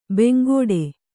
♪ bengōḍe